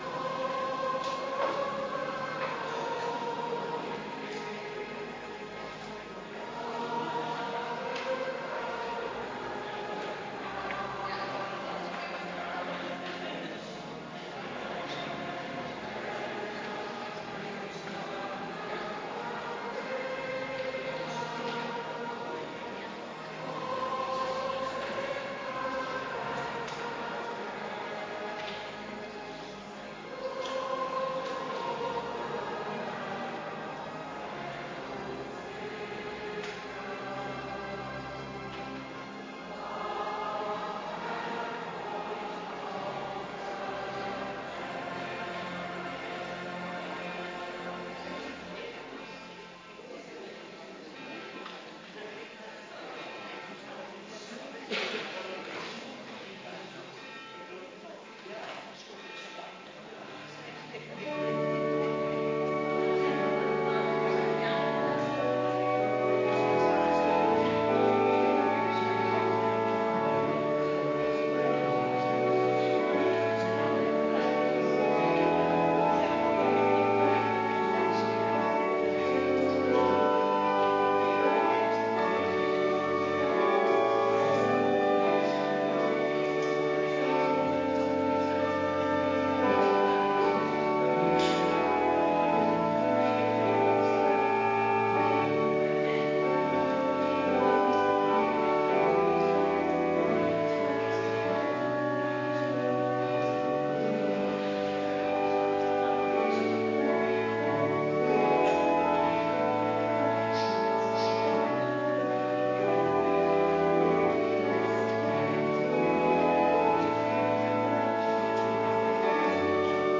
Kerkdiensten
Adventkerk Zondag week 34